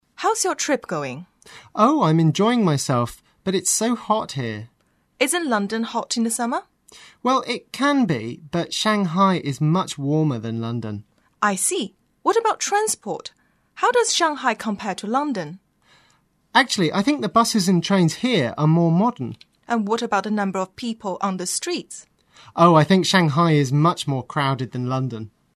english_48_dialogue_1.mp3